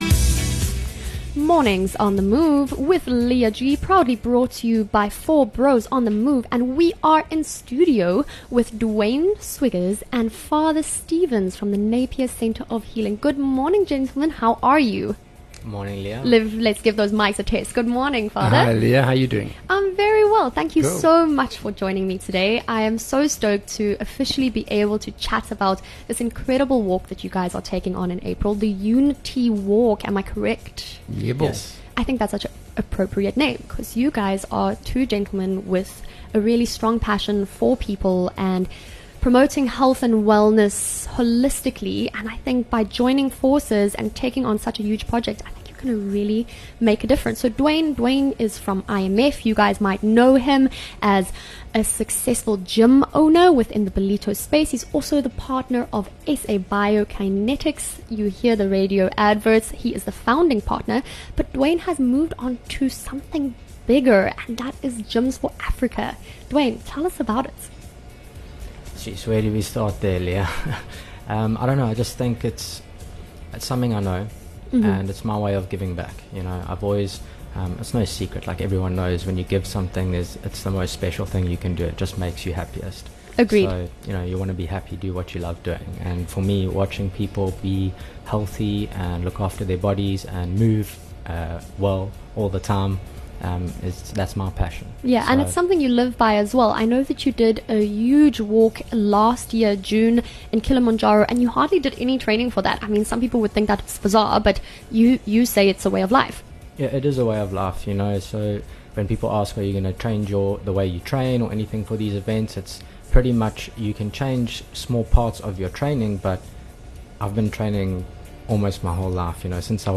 1 Mar In Conversation with Two Intrepid Walkers.